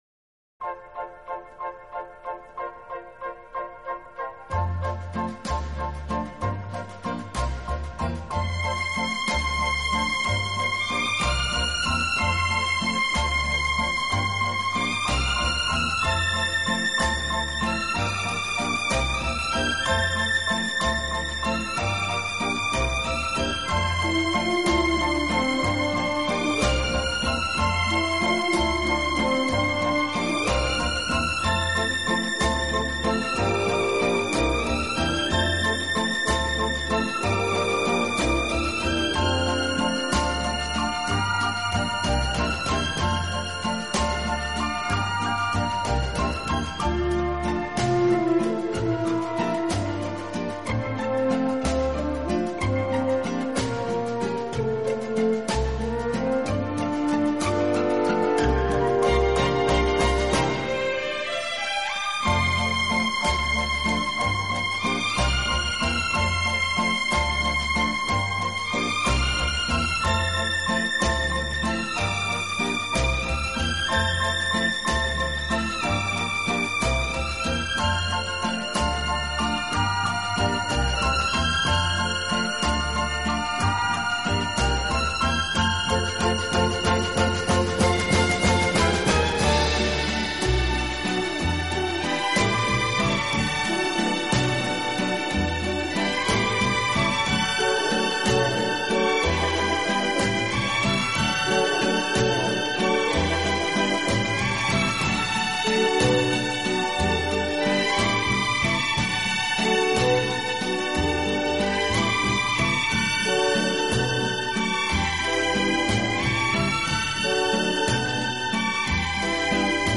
【浪漫纯音乐】
当您在聆听浪漫音乐的时候，优美，舒缓的音乐流水一样缓缓抚过心田，你会觉